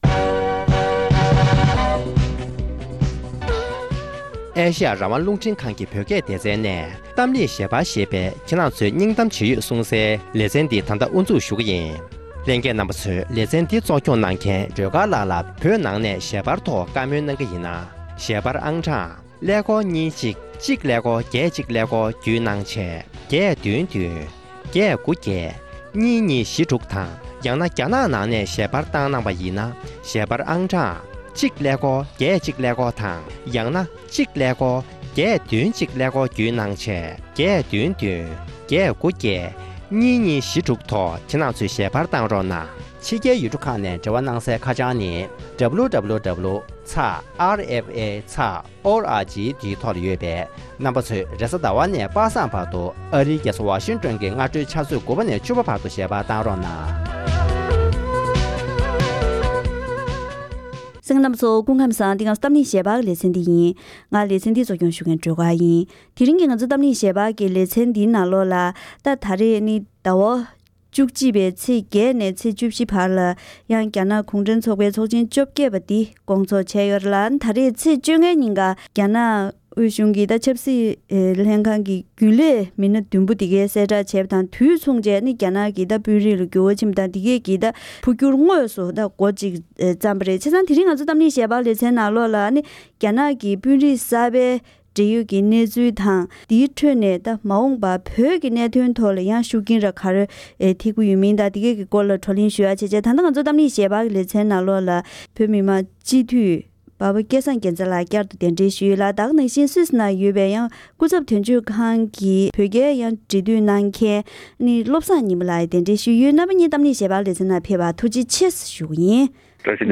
རྒྱ་ནག་གི་དཔོན་རིགས་གསར་པ་དང་དེ་བཞིན་བོད་ཀྱི་གནས་སྟངས་སྐོར་ལ་བགྲོ་གླེང་།